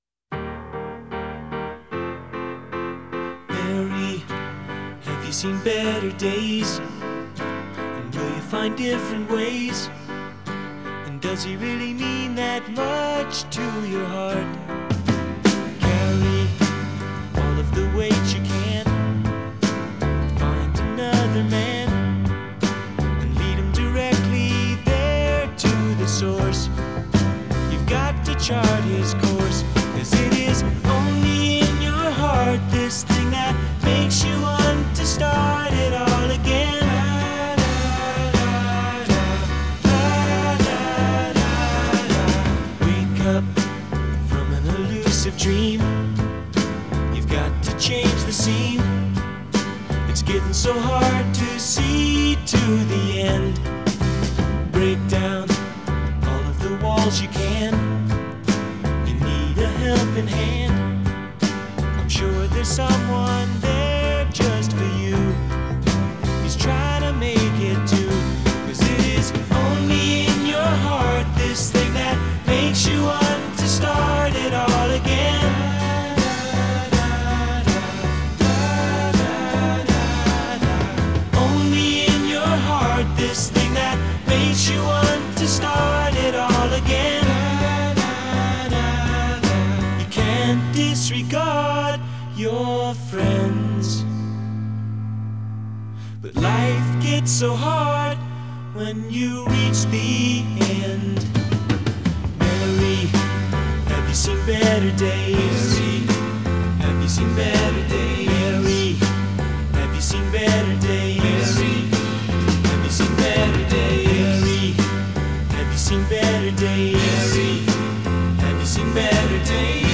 Genre: Folk-Rock.